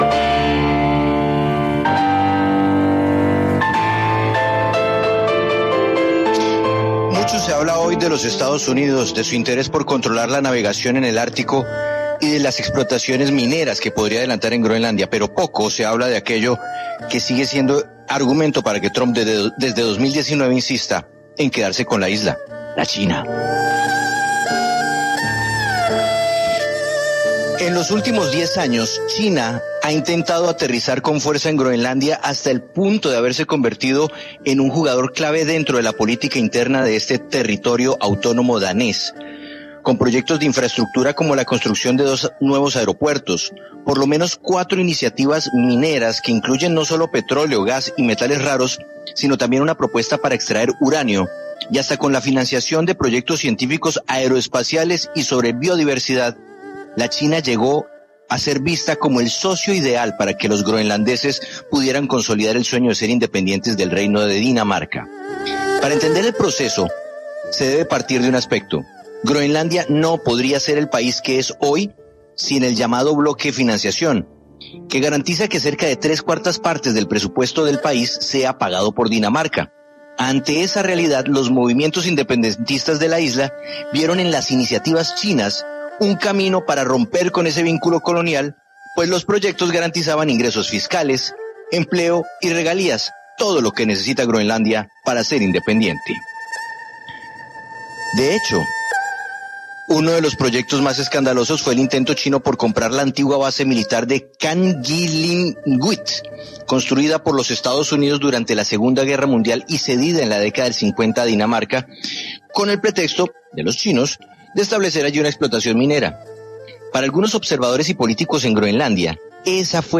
Caracol Radio desde Groenlandia: China sí estuvo a punto de controlar a Groenlandia